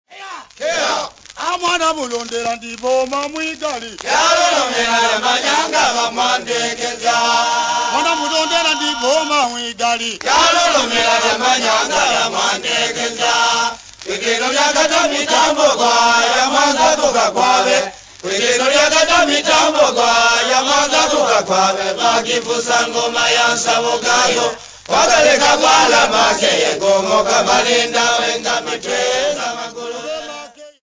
Hugh Tracey
Nyamwezi (African people)
Folk music
Field recordings
sound recording-musical
This troupe of dancers and singers was on tour at Mwanza from their home district of Tabora.